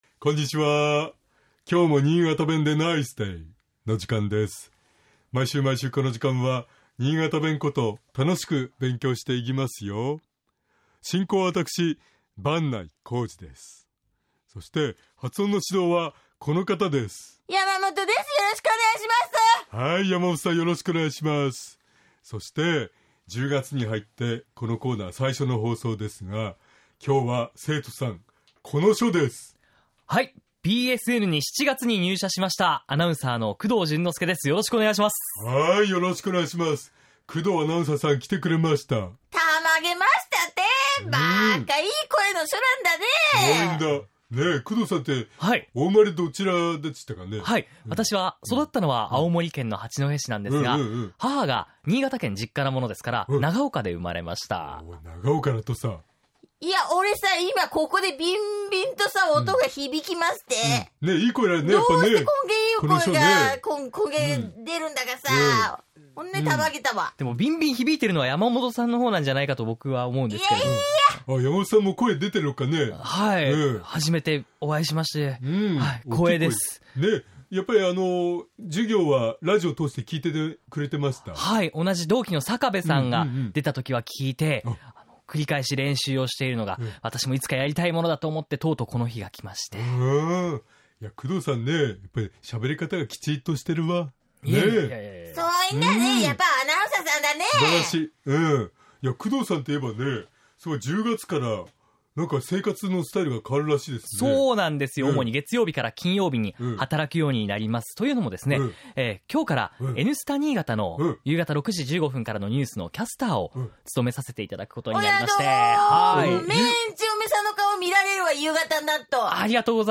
尚、このコーナーで紹介している言葉は、 主に新潟市とその周辺で使われている方言ですが、 それでも、世代や地域によって、 使い方、解釈、発音、アクセントなどに 微妙な違いがある事を御了承下さい。